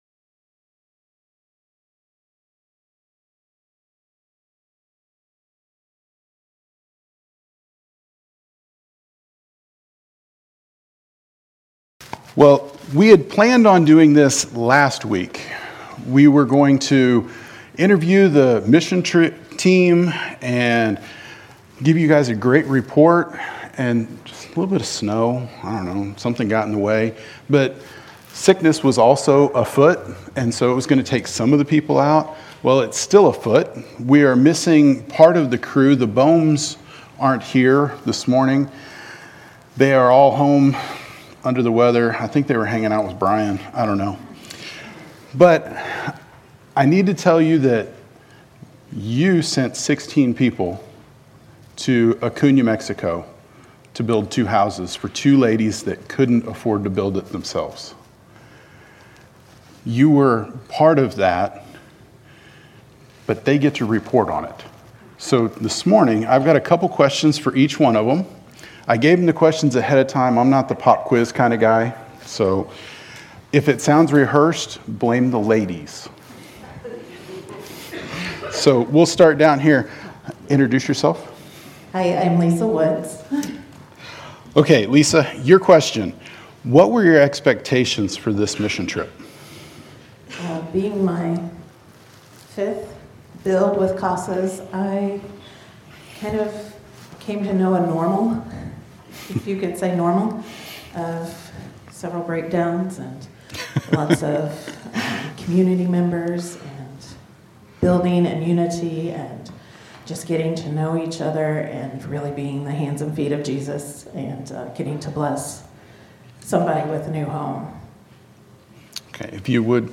Sermons | First Baptist Church Moberly